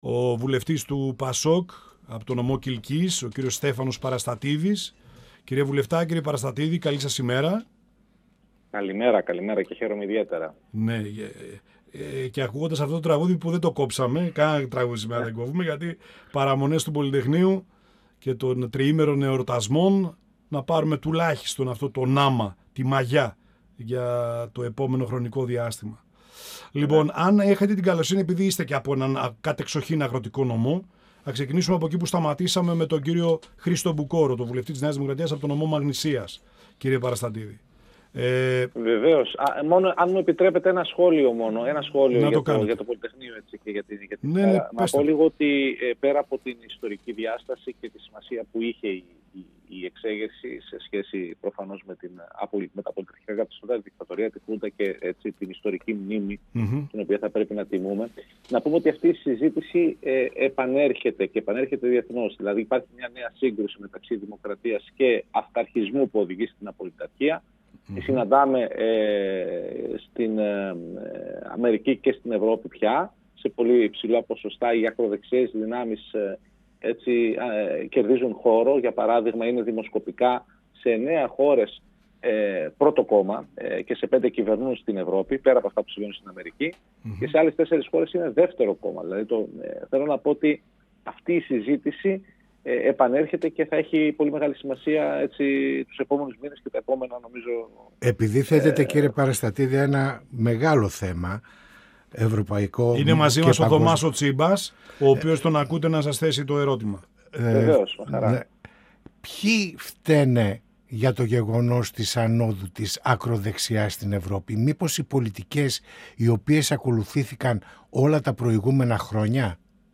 Στην κινούμενη άμμο της Κεντροαριστεράς, στα κενά στην εκπαίδευση και την παιδεία των ομογενών αναφέρθηκε ο Βουλευτής του ΠΑΣΟΚ Στέφανος Παραστατίδης, μιλώντας στην εκπομπή «Πανόραμα Επικαιρότητας» του 102FM της ΕΡΤ3.